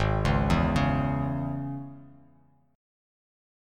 G#sus4#5 chord